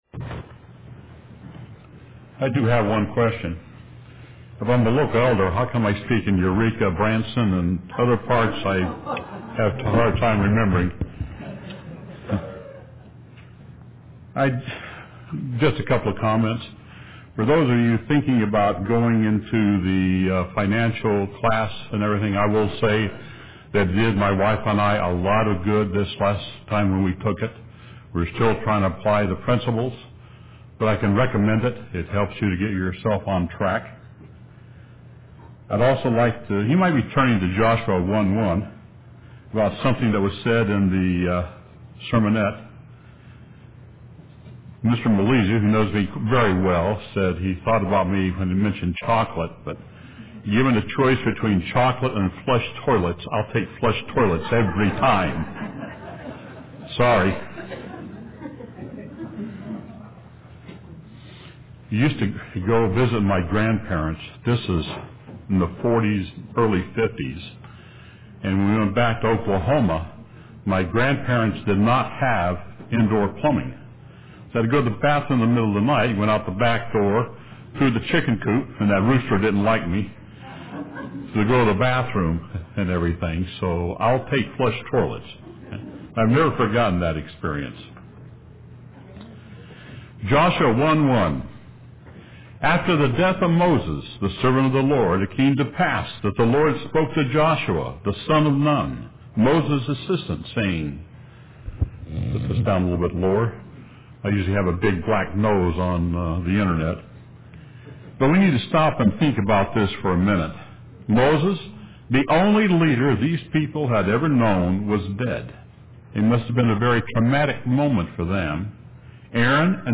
UCG Sermon Notes